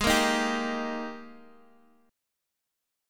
Eb/Ab Chord
Eb-Major-Ab-x,x,6,3,4,3.m4a